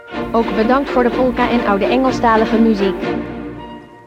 Onze Jingles / Unser Jingles